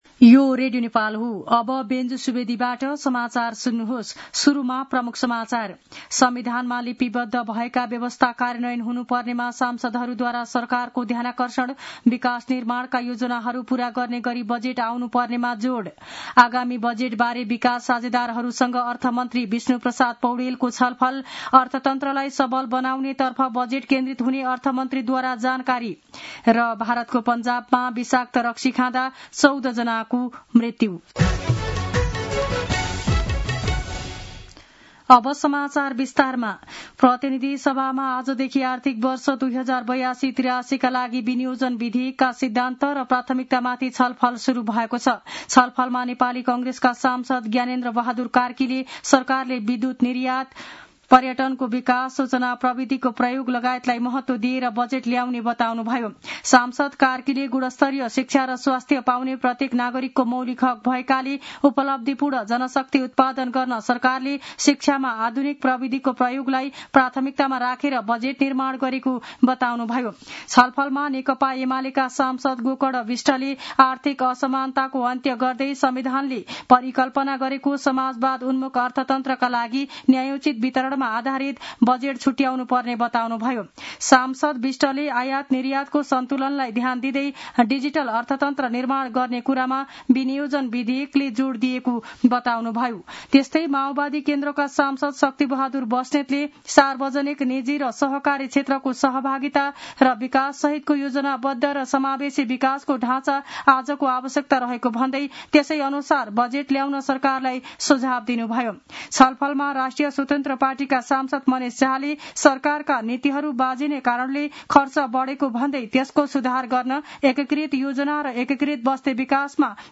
दिउँसो ३ बजेको नेपाली समाचार : ३० वैशाख , २०८२
3-pm-news-1-1.mp3